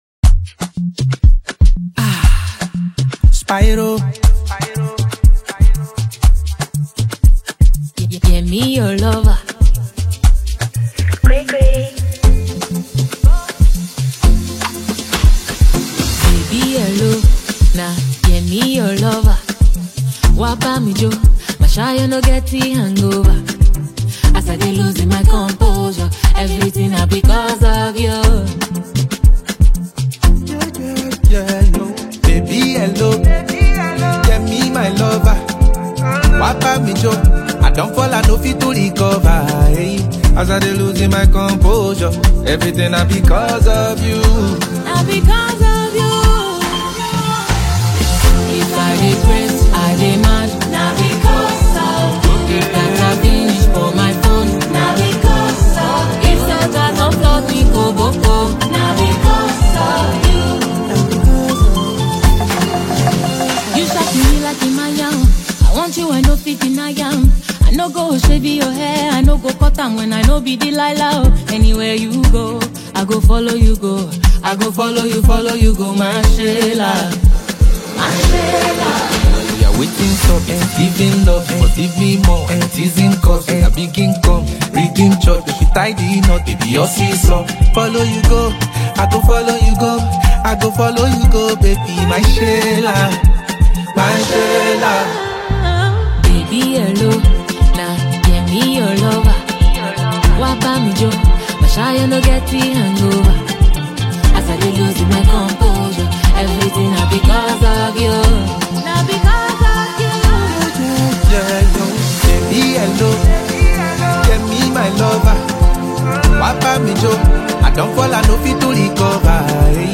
Afro-pop love anthem
smooth, reassuring vocals